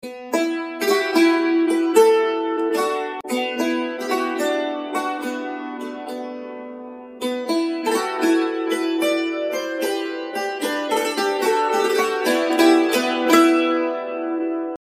Dulcimer